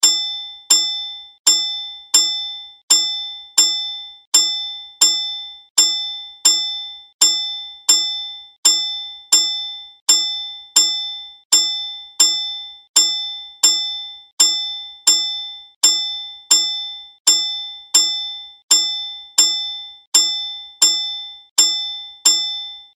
Railroad crossing sound ringtone free download